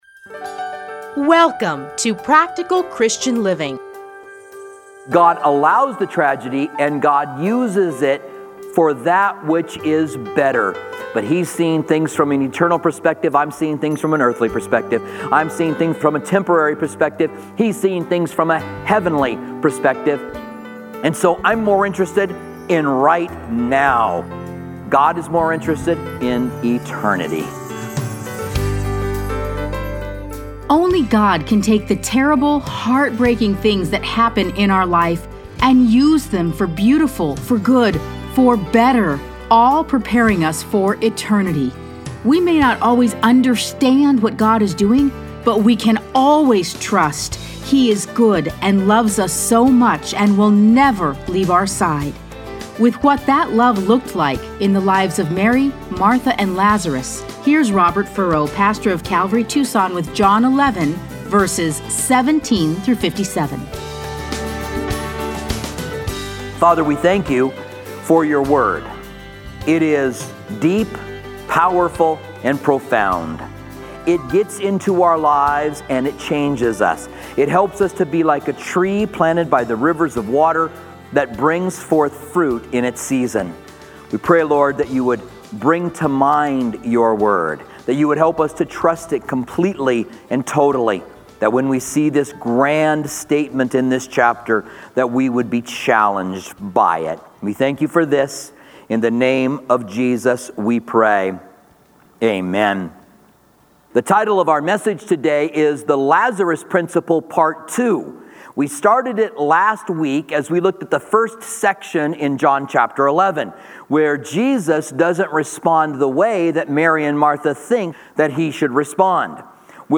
Listen to a teaching from John 11:17-57.